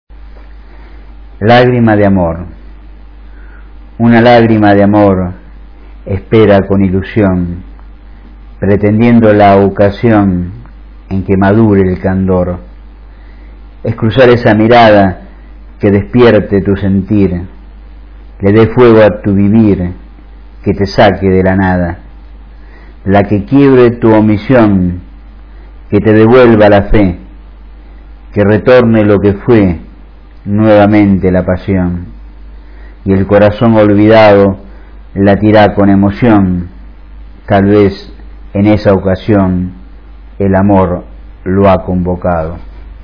Recitado por el autor (0:42", 168 KB)